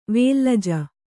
♪ vēllaja